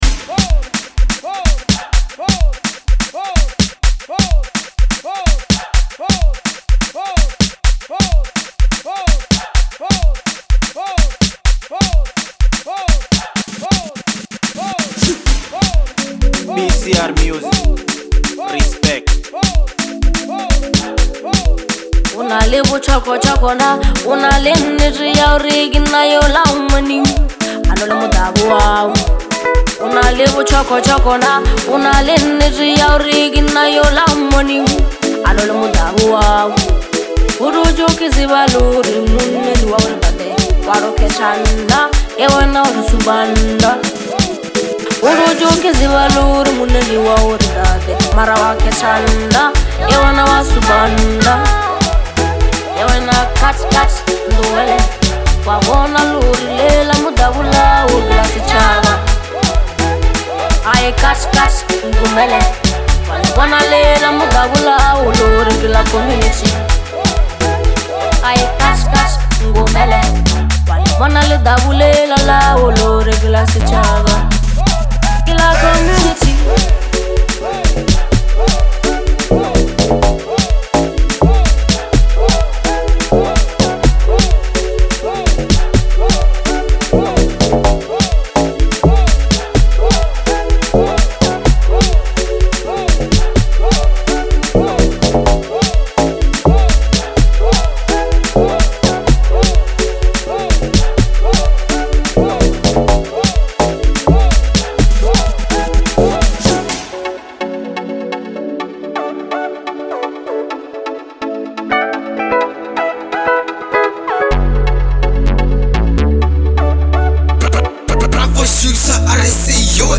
Genre: Bolo House / Lekompo